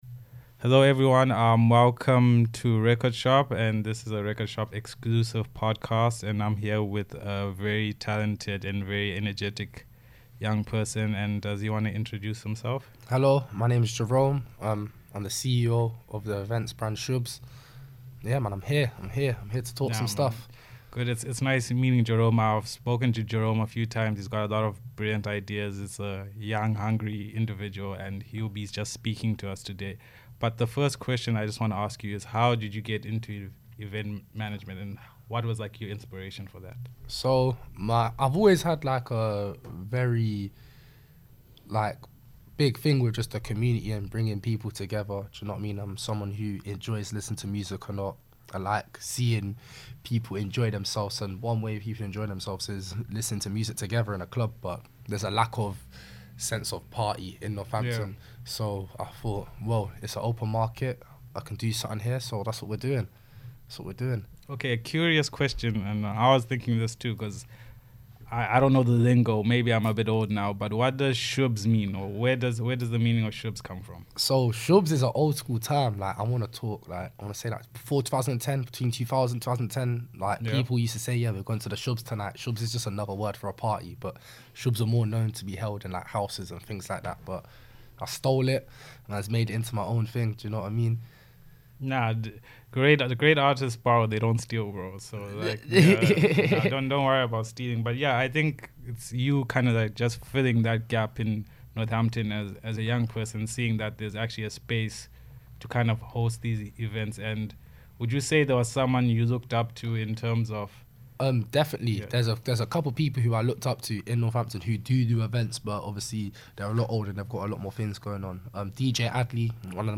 A Candid Conversation at Rekordshop Northampton In a recent podcast session hosted at Rekordshop Northampton